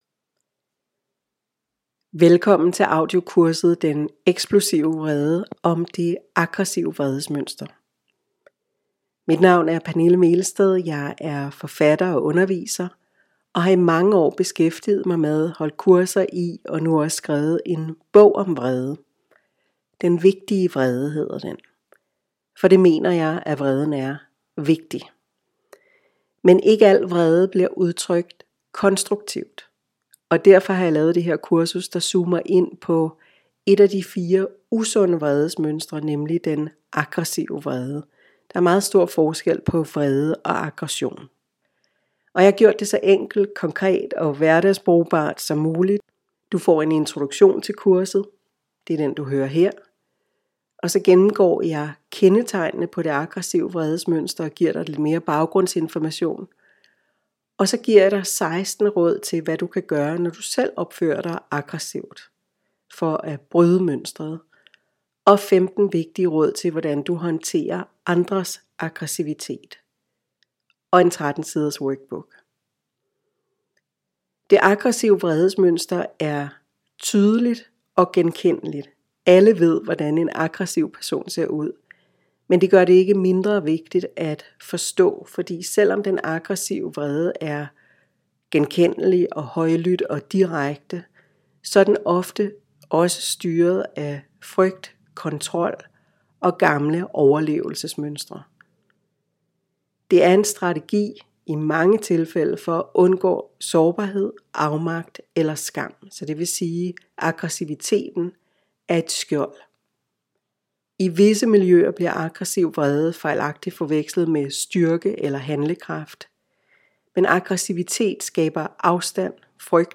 Audiokursus